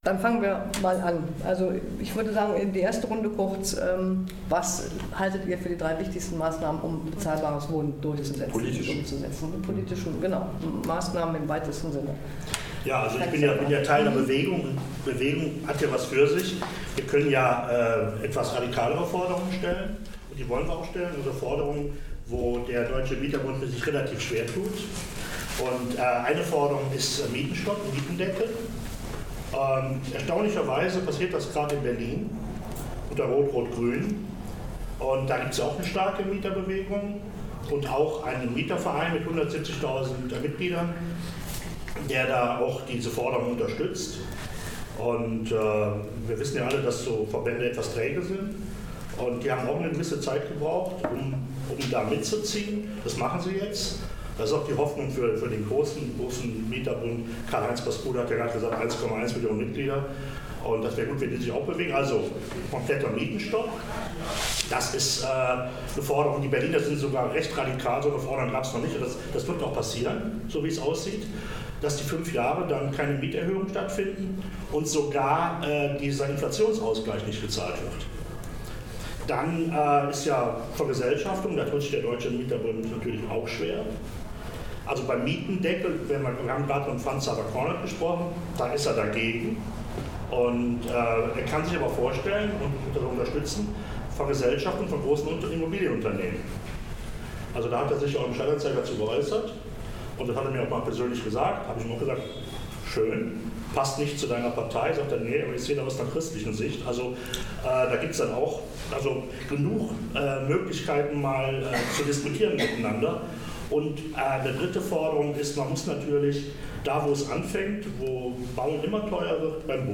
Anlässlich des 68. Deutschen Mietertages am 13. Juni 2019, zu dem auch Kanzlerin Angela Merkel geladen war, gab es in Köln eine Veranstaltung verschiedener Bewegungen zur Mietenproblematik. Die Ignoranz der Politik, falsche Weichenstellungen im Wohnungsbau und die Überlassung der Probleme dem vermeintlichen Heilsbringer „Freier Markt“ haben in Teilen der Republik zu katastrophaler Wohnungsnot, unbezahlbaren Mieten und enorm steigenden Obdachlosenzahlen geführt, lauten die Vorwürfe.